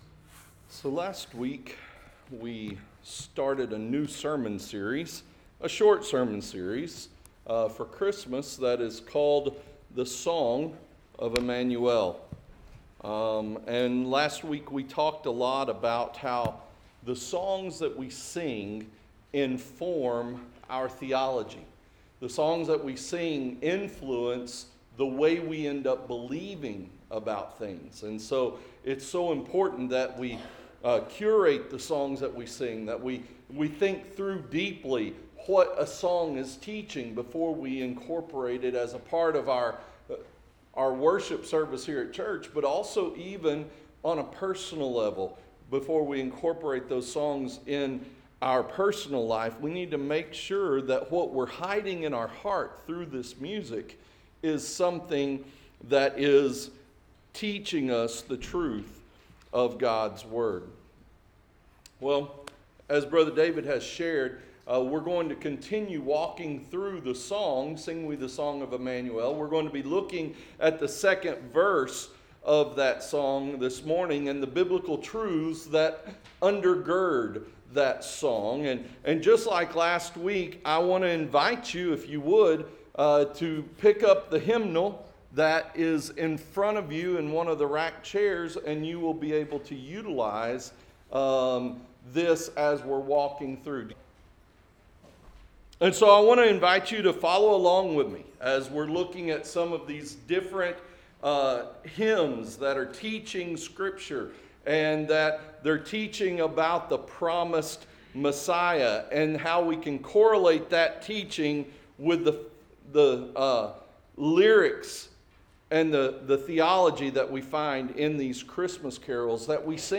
Last week, we started a short sermon series for Christmas called: “The Song of Emmanuel.”